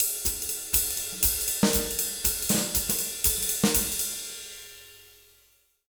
240SWING03-L.wav